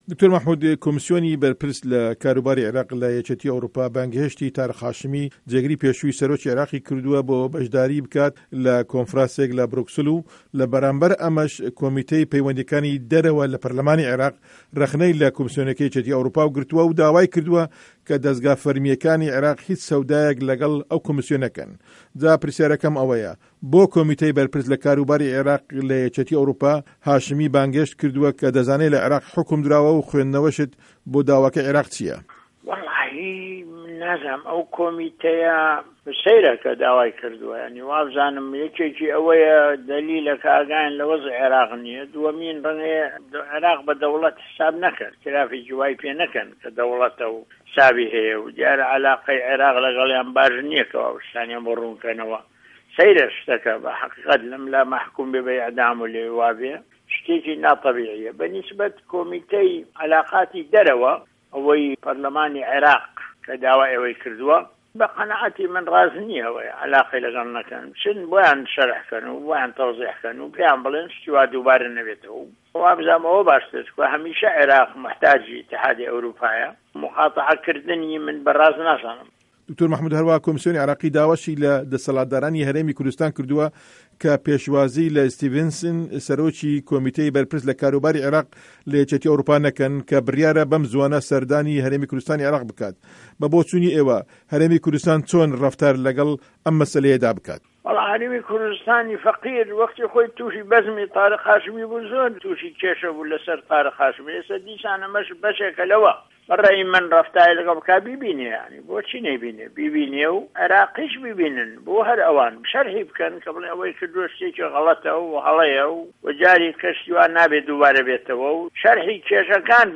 وتووێژی مه‌حمود عوسمان